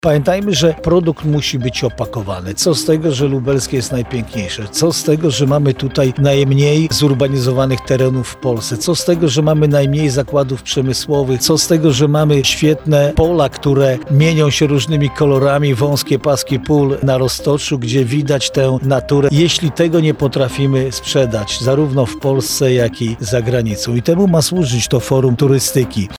Będziemy także chcieli uczyć małych przedsiębiorców z branży turystycznej jak lepiej promować nasz region w social mediach– podkreślał w Porannej Rozmowie Radia Centrum Jarosław Stawiarski, Marszałek Województwa Lubelskiego